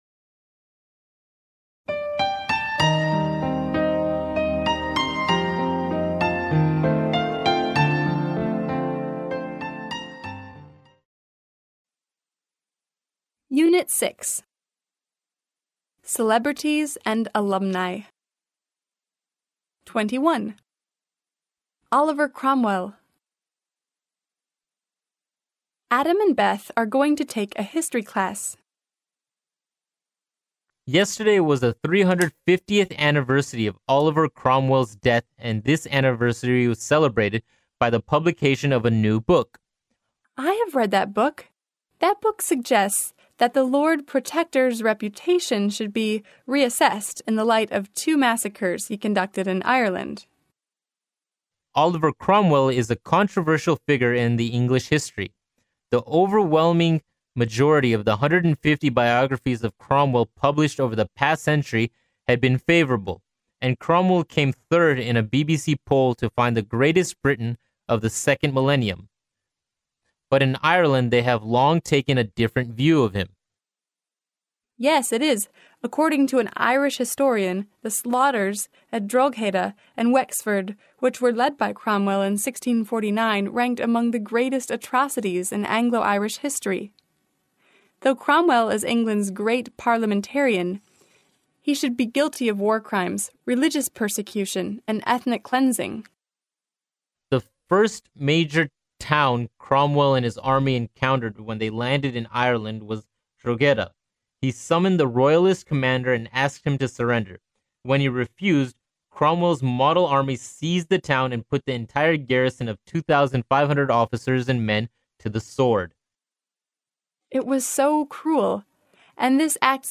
剑桥大学校园英语情景对话21：颇具争议的克伦威尔（mp3+中英）